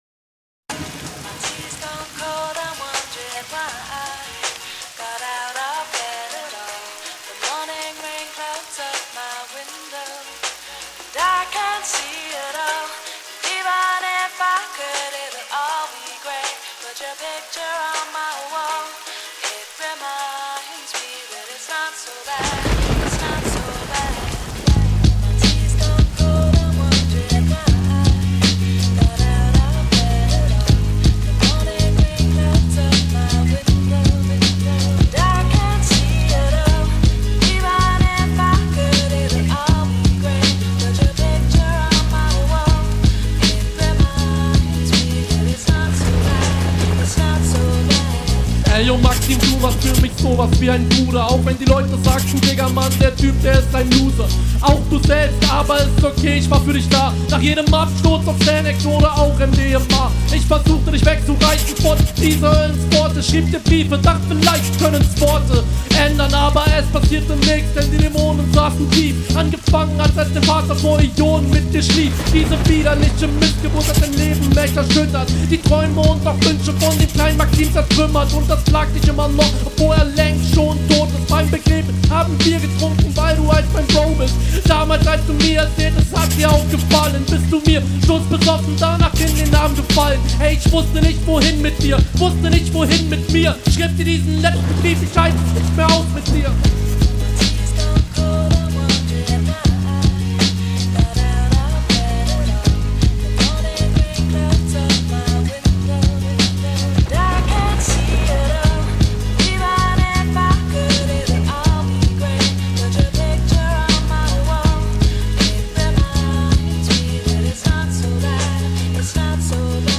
Flow: passender stimmeinsatz und delivery, abwechslungsreiche flowpatterns leider teilweise nicht ganz aufm biet Text: nettes …
Flow: Nicer Flow. Ich mag die Triolen. Passen sehr gut. Text: Guter Gegnerbezug (z.B. das …
Der Stimmeinsatz ist relativ cool, aber etwas zu drucklos für den Beat.